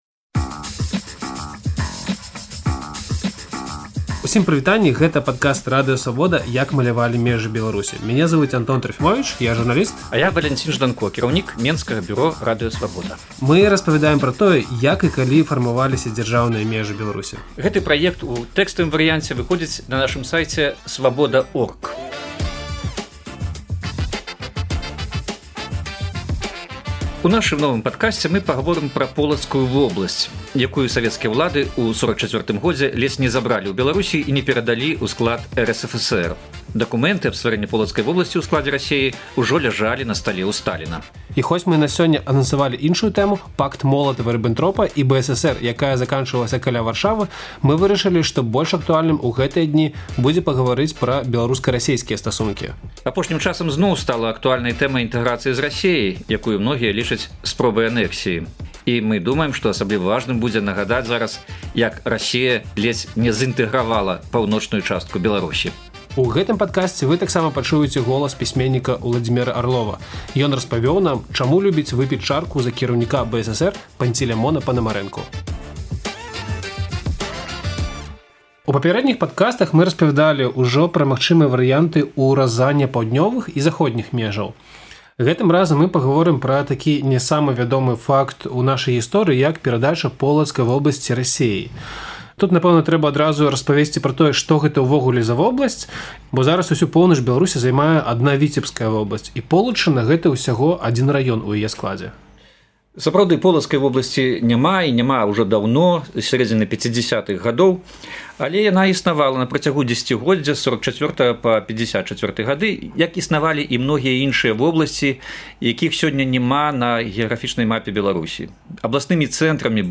размаўляюць пра плян савецкіх уладаў выключыць са складу БССР Полацкую вобласьць.